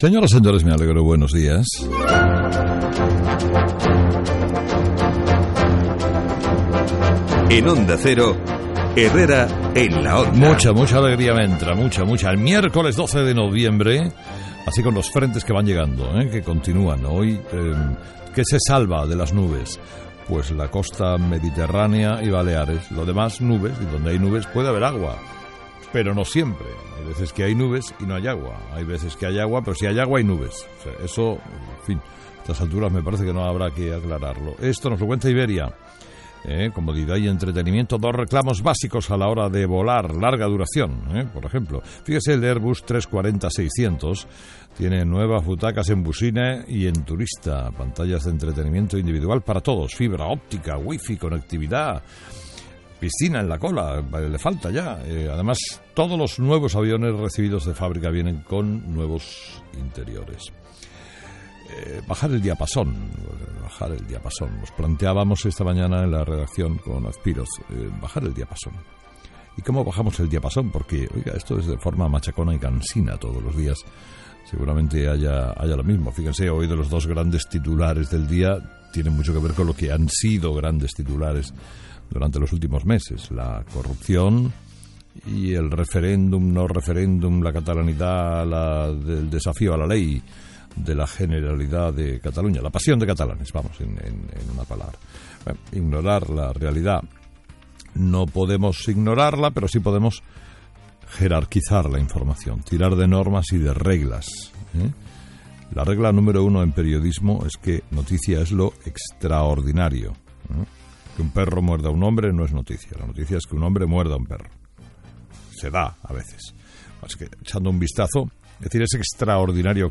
Carlos Herrera toma como premisa en su editorial una regla del periodismo de que "noticia es lo extraordinario".